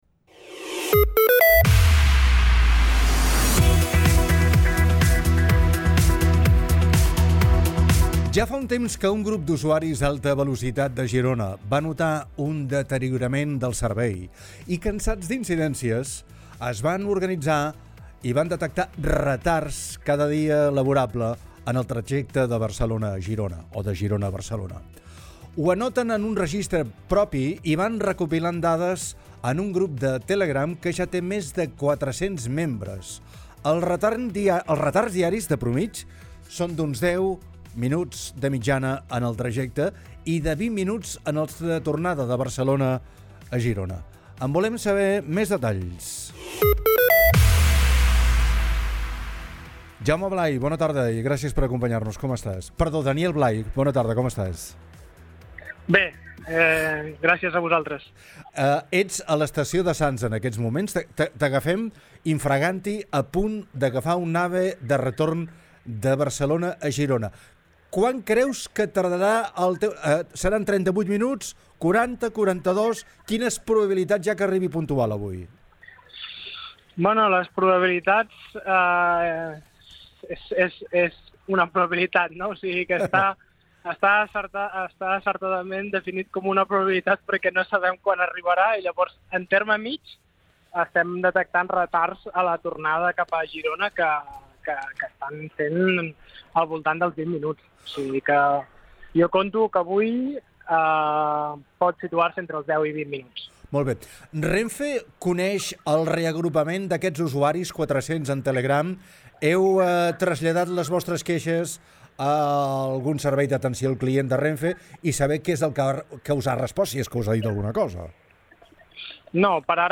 ha estat entrevistat